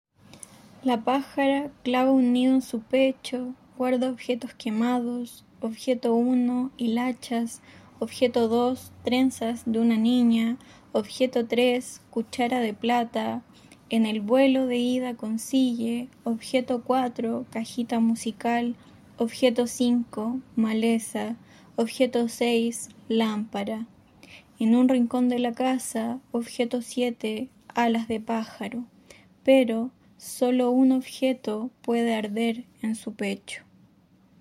lectura-poema-34-online-audio-converter.com_.mp3